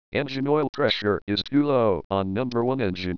voice annunciated audible alarm or both.
EngineOilPressureIsTooLow.wav